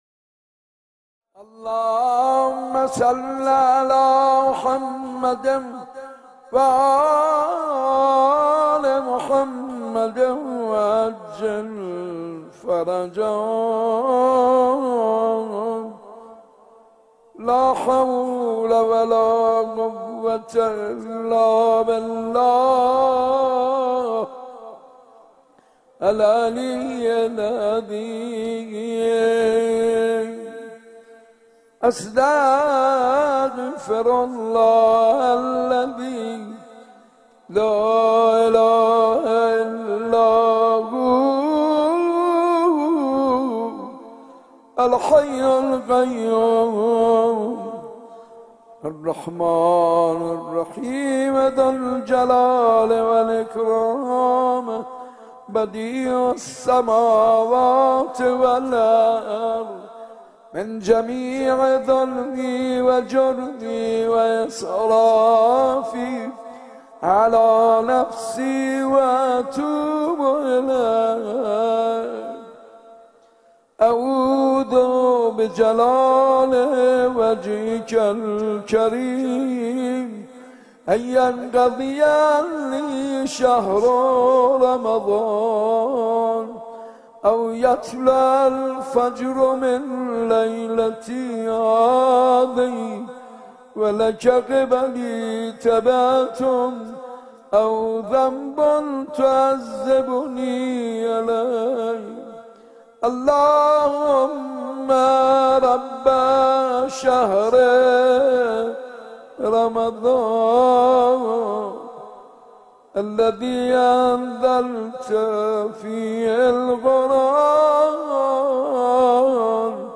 مناجات‌خوانی حاج منصور ارضی در شب پنجم ماه رمضان 98 صوت و فیلم - تسنیم
به گزارش خبرنگار فرهنگی باشگاه خبرنگاران پویا، یکی از مساجد قدیمی استان تهران که در شب‌های ماه مبارک رمضان با استقبال بسیار خوبی از سوی مردم مواجه می‌شود، مسجد ارک تهران است که هر ساله با آغاز ماه رمضان حال و هوای خاصی به خود می‌گیرد.
شب گذشته و در پنجمین شب ماه مبارک رمضان حاج منصور ارضی مداح پیشکسوت اهل‌بیت (ع) همچون سال‌های گذشته به مداحی و مناجات‌خوانی پرداخت که صوت و فلیم آن را تقدیم مخاطبان می‌شود.